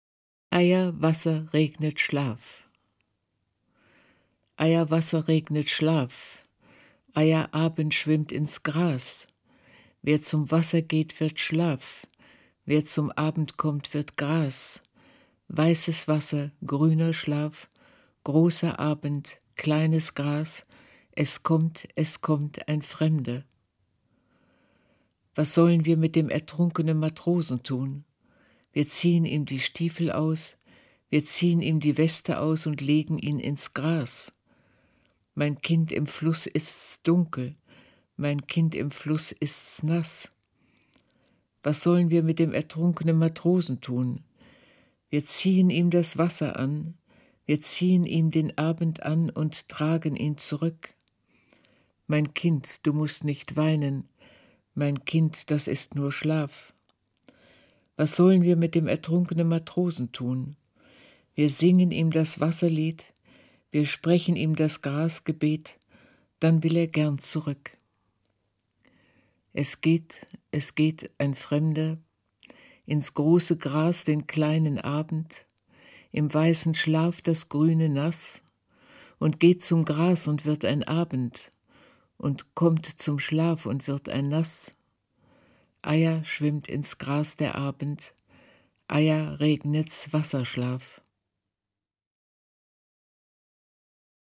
von Elisabeth Borchers 2003 selbst gelesen
Elisabeth-Borchers-liest-_Eia-Wasser-regnet-Schlaf_-2003.mp3